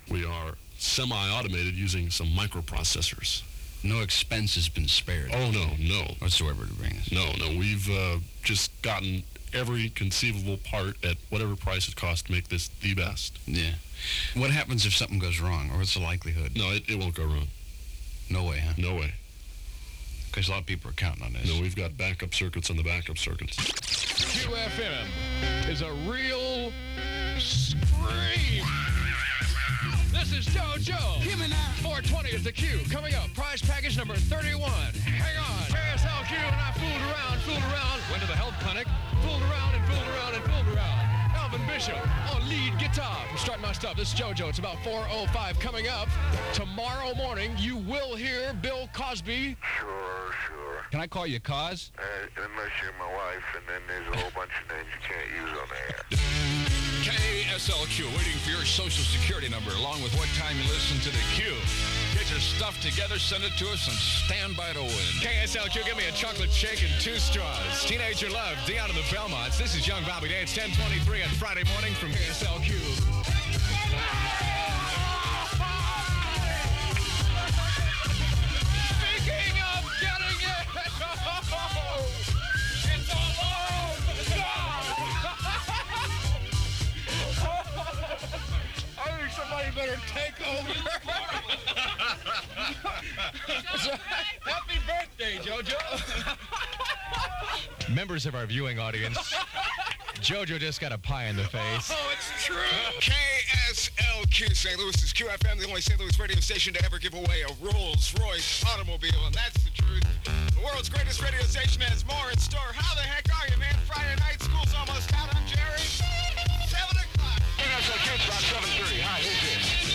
KSLQ Various Announcers aircheck · St. Louis Media History Archive
Original Format aircheck